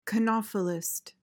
PRONUNCIATION:
(kuh-NOF-uh-list)